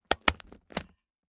fo_stapler_03_hpx
Papers are stapled together. Paper, Staple